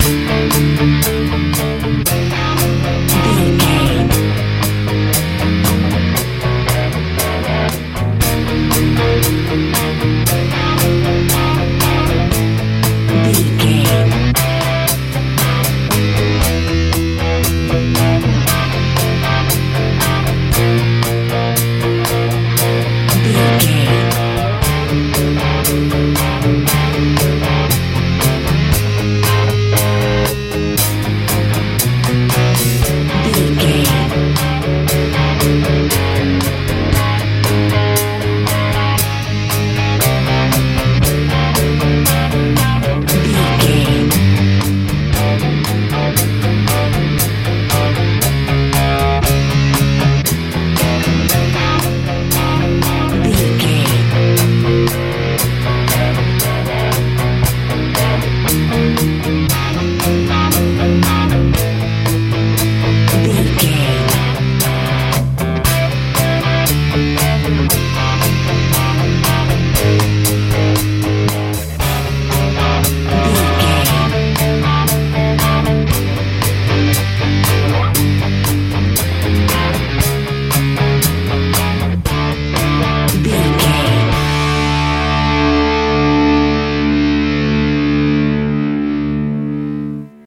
nu metal punk feel
Ionian/Major
groovy
funky
bass guitar
drums
electric guitar
lively